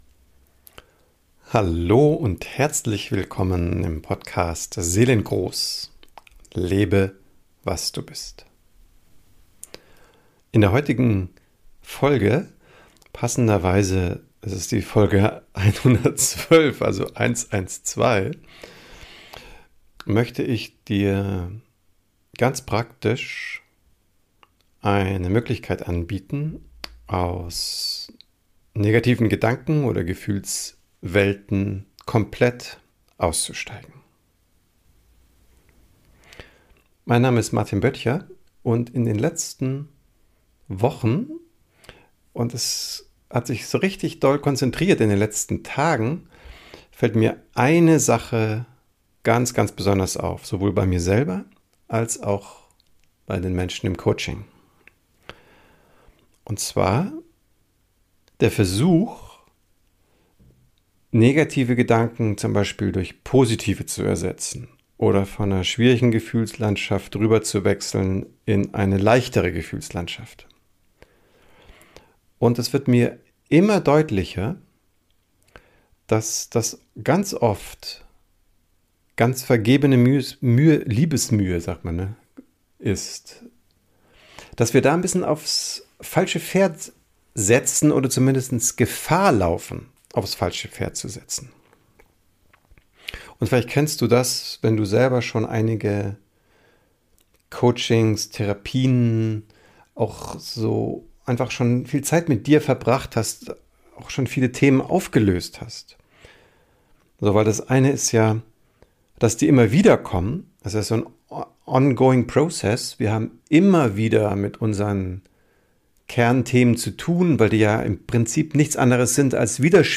In dieser Folge beschreibe ich einen verblüffend sanften Weg aus negativen Gedanken und Gefühlen auszusteigen. Ab Minute 20 gibt es eine geführte Meditation dazu. Für alle, die etwas tiefer einsteigen wollen in das Phänomen negativer Gedanken und Emotionen beschreibe ich einführend einige Hintergründe.